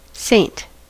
Ääntäminen
France: IPA: [sɛ̃]